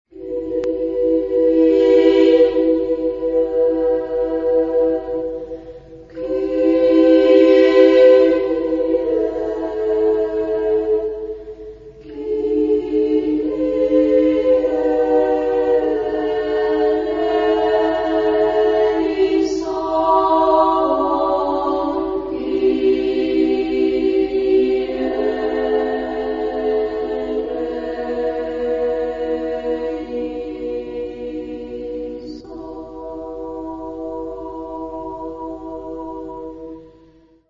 Genre-Style-Forme : Messe ; Sacré
Type de choeur : SSAA  (4 voix égales de femmes )
Tonalité : mi mineur